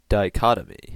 Ääntäminen
US : IPA : [daɪ.ˈkɑt.ə.mi] UK : IPA : /daɪ.ˈkɒt.ə.mi/